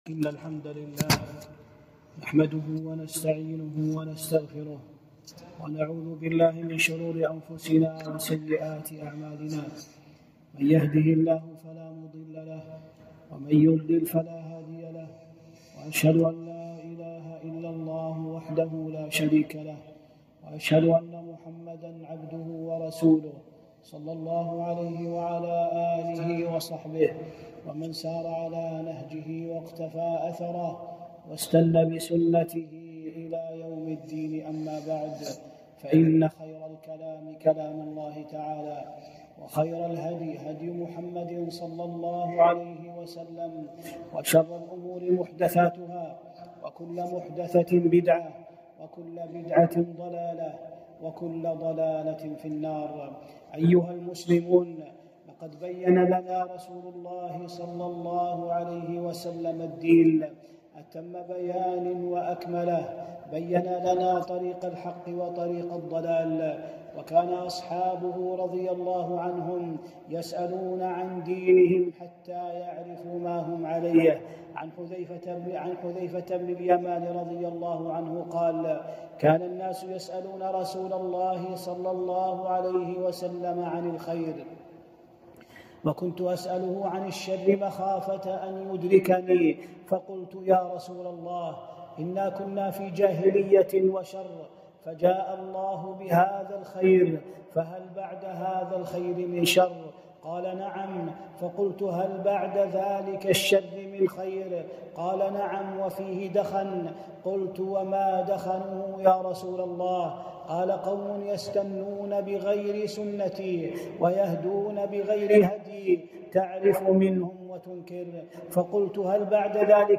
خطبة - التحذير من جماعة التبليغ